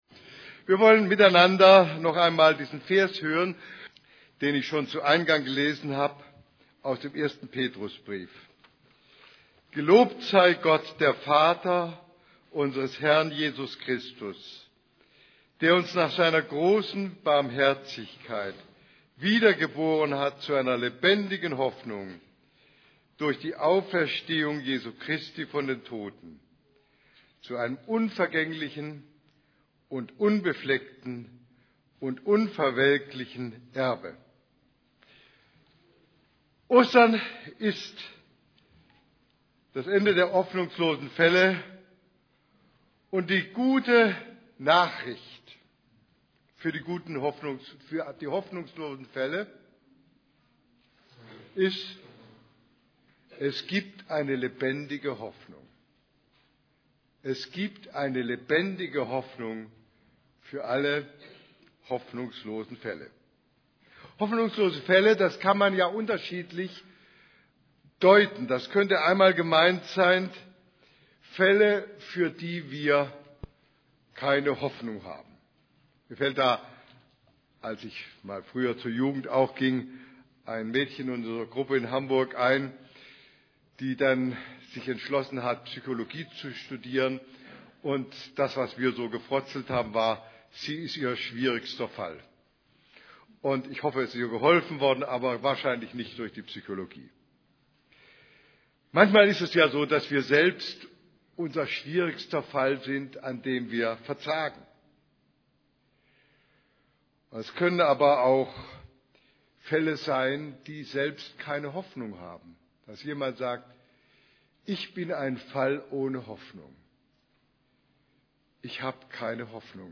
> Übersicht Predigten Ostern: Das Ende hoffnungsloser Fälle Predigt vom 04.